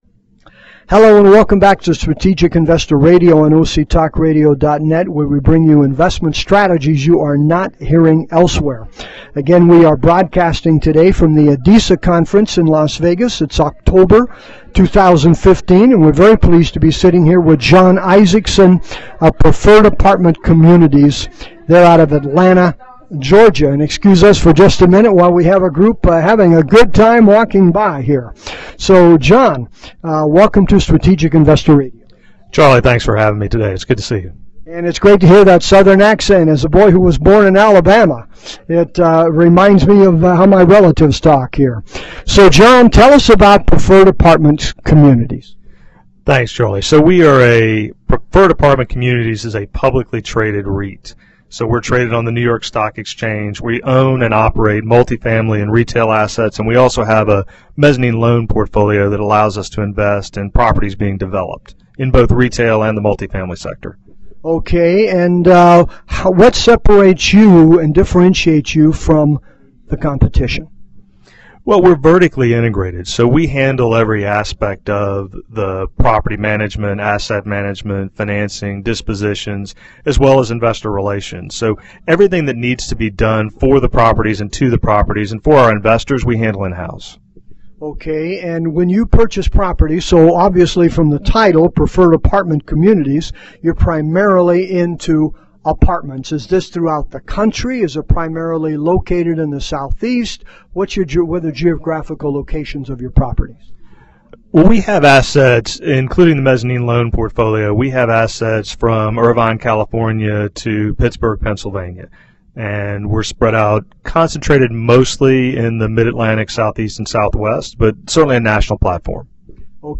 This interview is not an offer to sell, nor an endorsement of, the fun